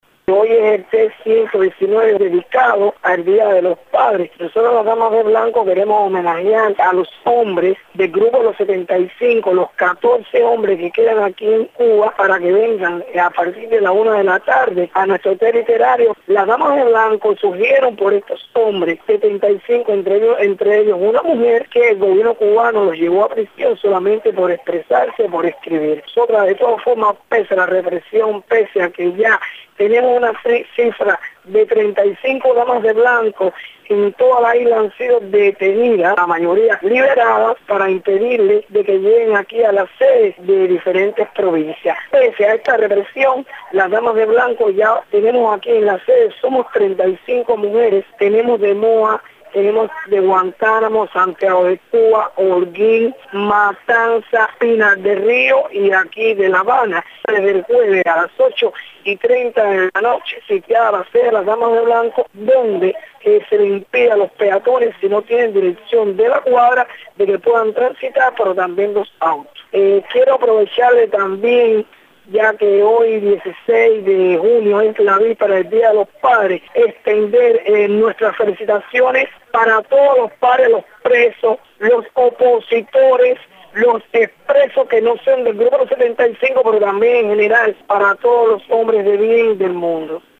Declaraciones de Berta Soler